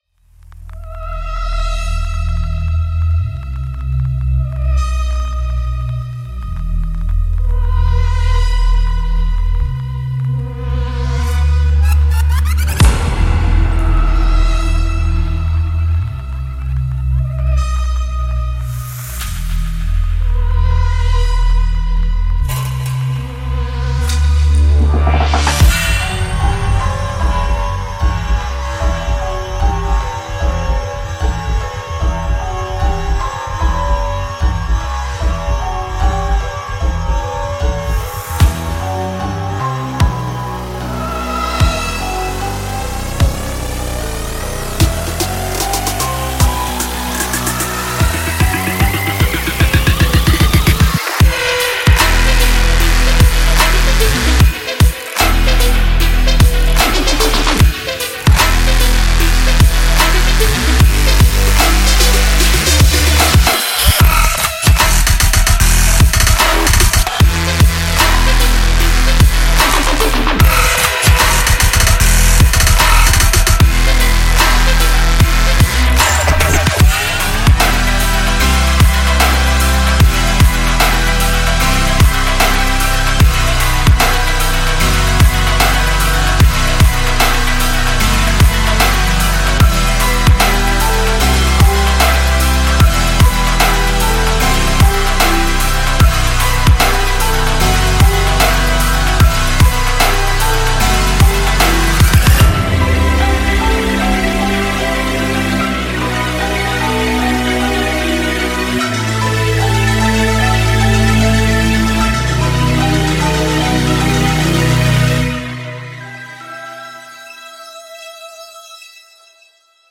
用循环包装，MIDI剪辑，声音效果和福利，闹鬼的是准备好你心里有什么怪异的项目。